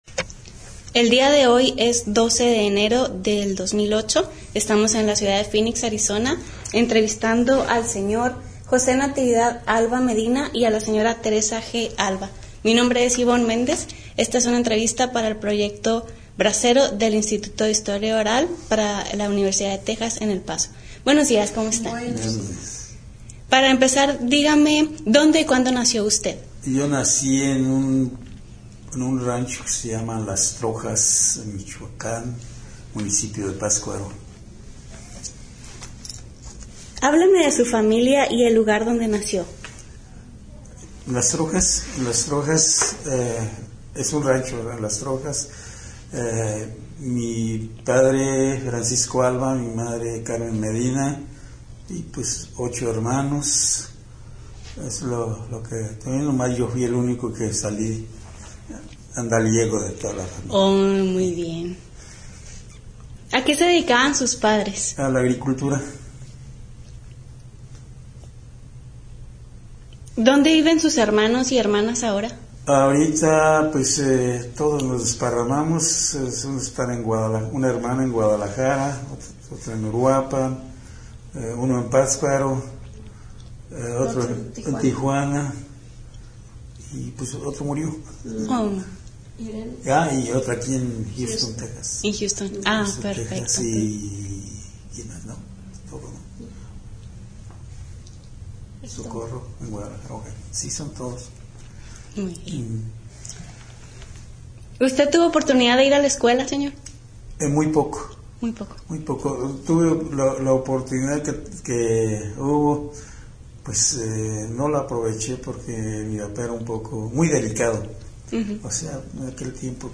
Location Phoenix, Arizona